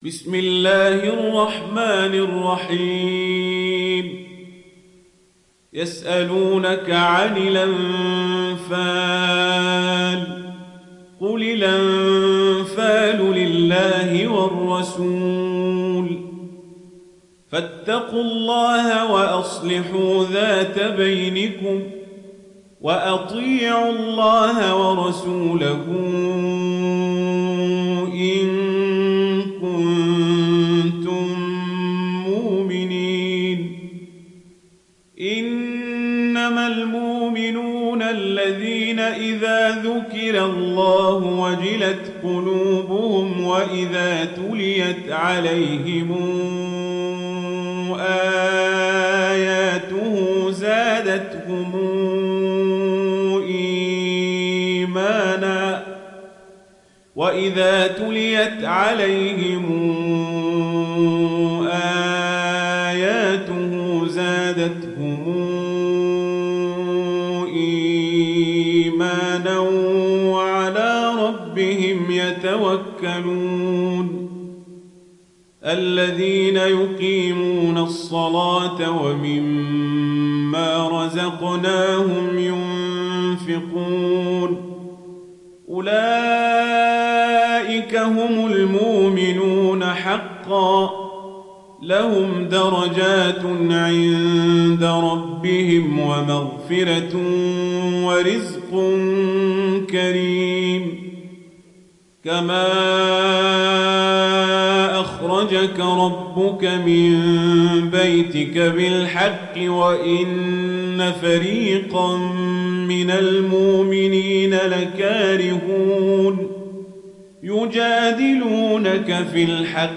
دانلود سوره الأنفال mp3 عمر القزابري روایت ورش از نافع, قرآن را دانلود کنید و گوش کن mp3 ، لینک مستقیم کامل